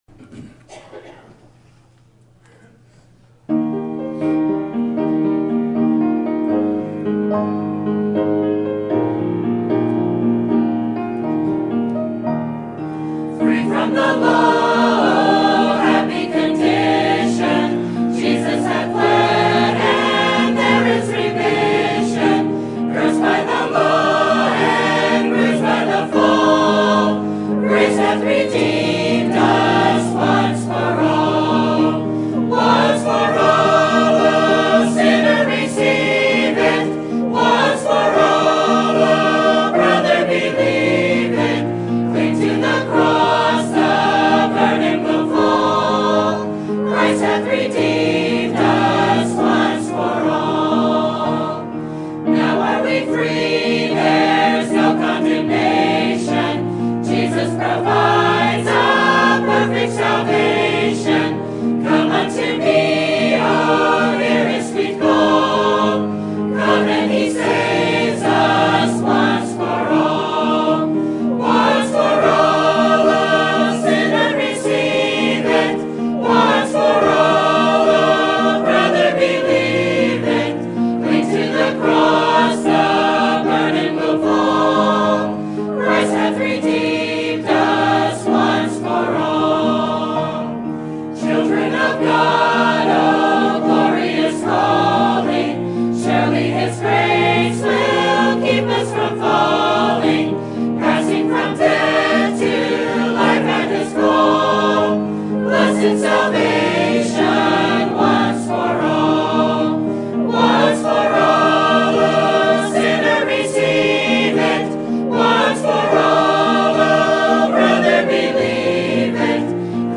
Sermon Topic: General Sermon Type: Service Sermon Audio: Sermon download: Download (29.66 MB) Sermon Tags: Psalms David Repent Forgiveness